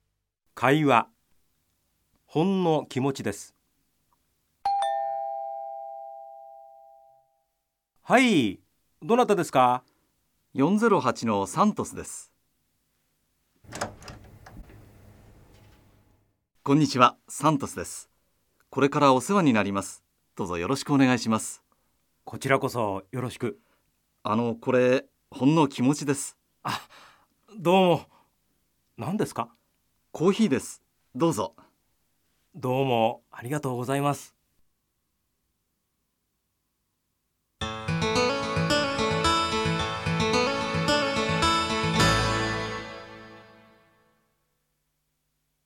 Minano Nihongo Bài 2: Hội thoại